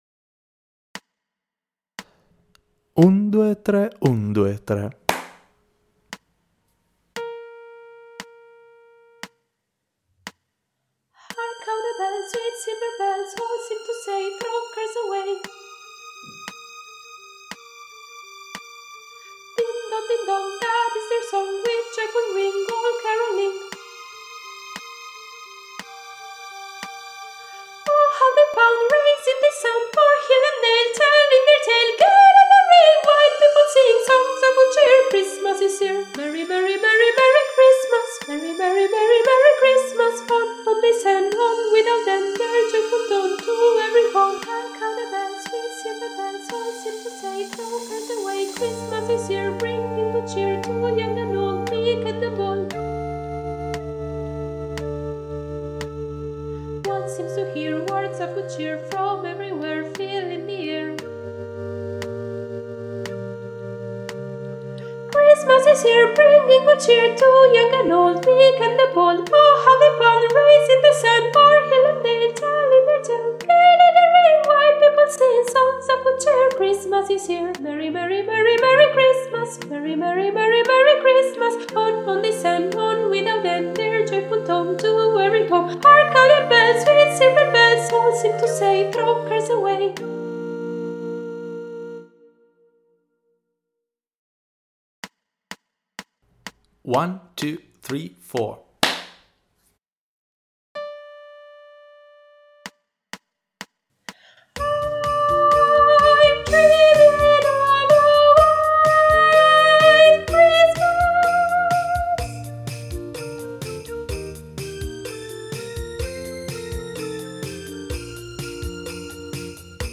Per partecipare alla nostra iniziativa dovresti studiare la canzone di Natale di Feniarco: un arrangiamento con 10 melodie natalizie, che si alternano tra i diversi registri vocali, realizzato appositamente per l'occasione da Alessandro Cadario.
• una parte a due voci per i cori femminili
Mp3 traccia guida coro femminile - soprano